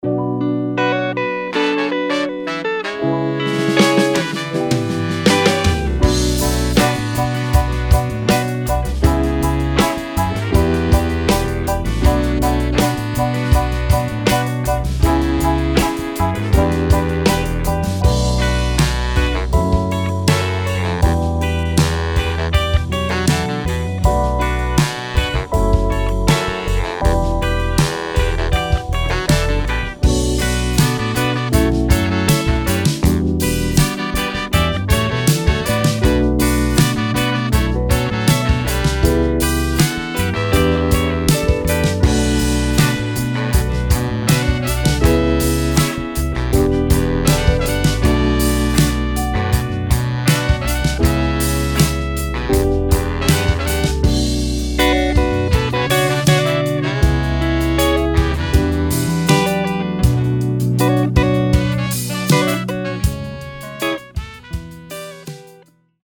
Packed with the rich, vibrant sounds of funk and soul, this collection offers an array of live instrumentation that brings an authentic, retro feel to your music.
16 Bass Guitar Loops: Deep, punchy basslines that form the backbone of your tracks.
5 Brass Ensemble Loops: Bold brass riffs to energize your compositions.
12 Drum Loops: Tight, rhythmic drum beats that drive the groove.
4 Guitar Ensemble Loops: Smooth, soulful guitar lines to elevate your production.
20 Guitar Loops: Funky rhythms and licks that add texture and flavor.
5 Organ Loops: Classic organ sounds to create that unmistakable vintage vibe.
6 Piano Loops: Soulful piano progressions for extra harmonic depth.
10 Sax Loops: Smooth, sultry saxophone melodies for a true funk feel.
5 Trumpet Loops: Sharp, vibrant trumpet sections that cut through the mix.
Funk-Soul-Ensemble-Vol-4.mp3